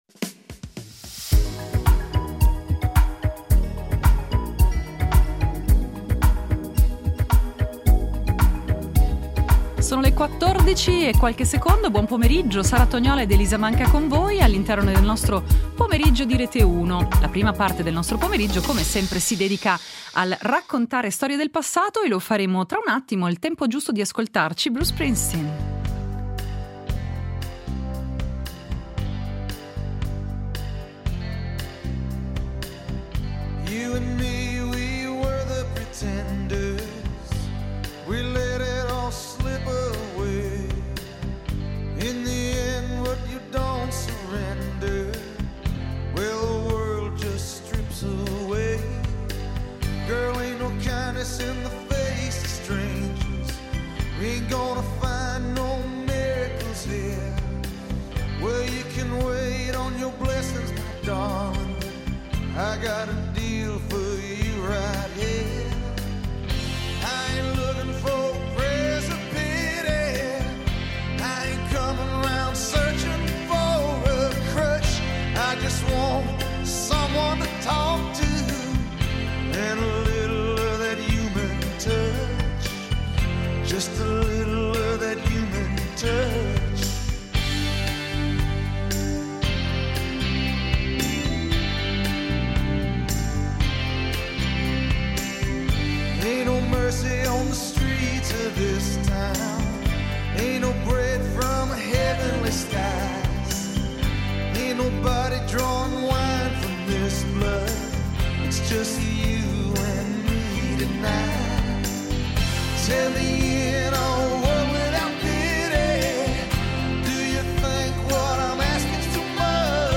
In Tracce, estratti d’archivio sulla Fiera di San Martino, ascoltando una puntata di Fatti Vostri